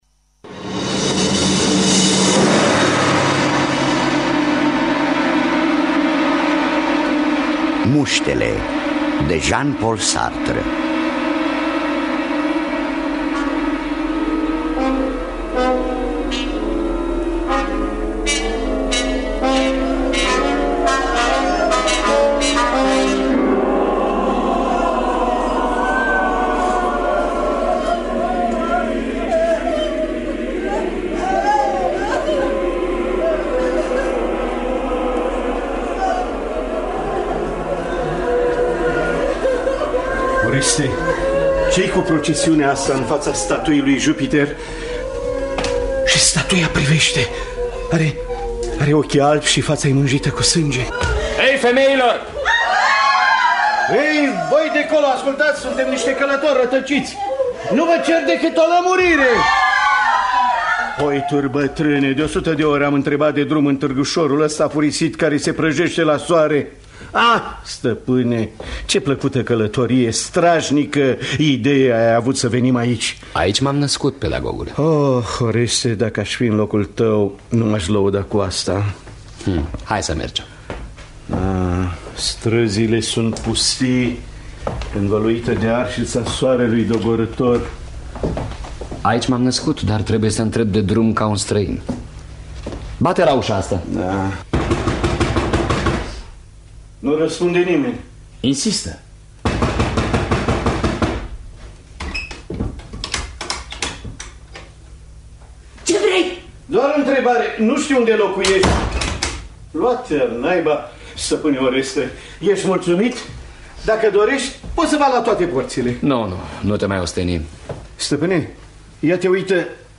Muștele de Jean-Paul Sartre – Teatru Radiofonic Online